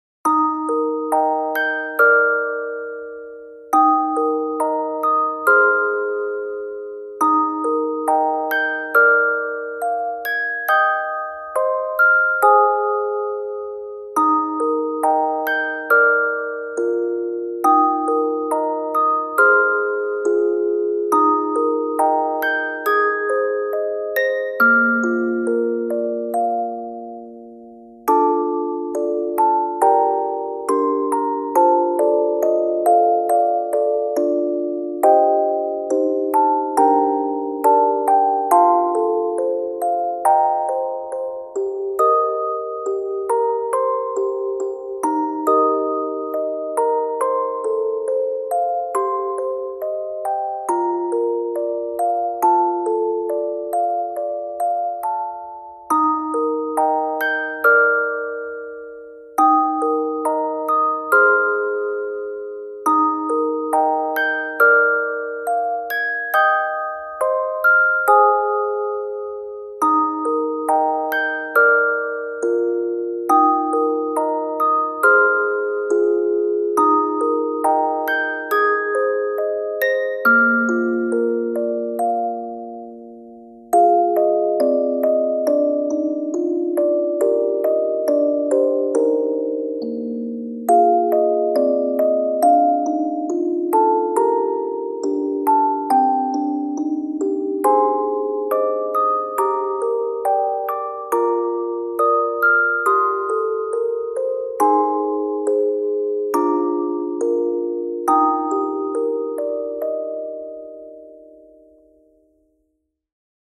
落ち着いた雰囲気になれるオルゴールBGMです。
BGM オルゴール バラード ヒーリング リラックス 春 冬 静か 優しい 癒し 落ち着く 幻想的 穏やか 夜